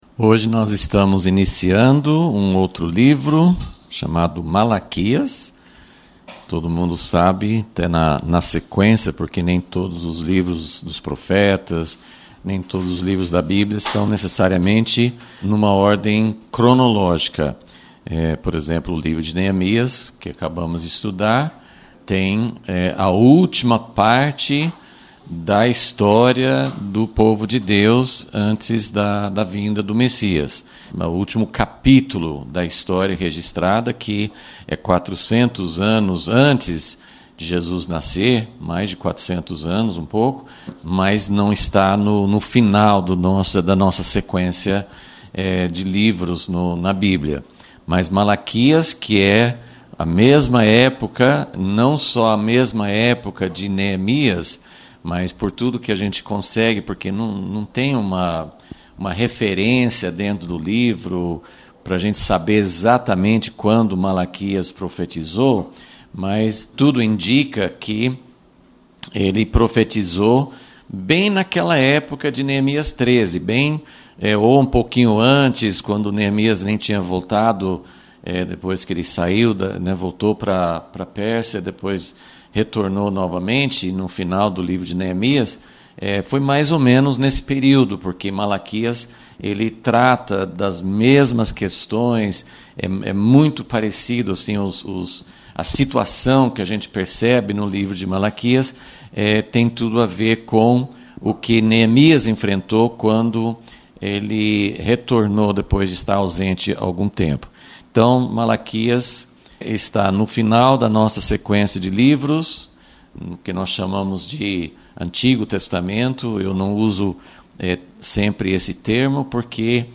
Aula 1 – Vol.37 – A primeira palavra de Malaquias